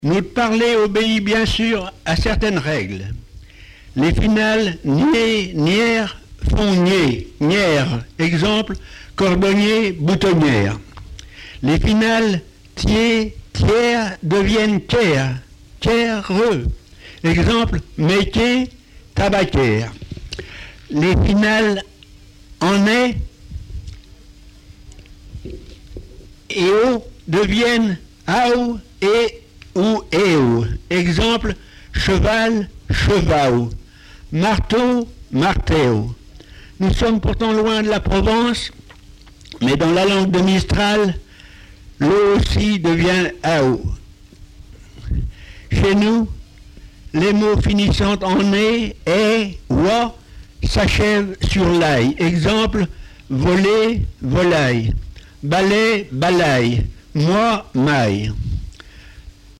lecteur pays de Retz inconnu
textes en patois et explications sur la prononciation
Témoignage